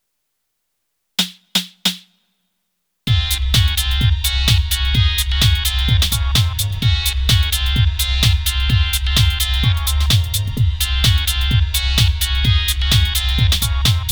I made a quick edit and got some better low end.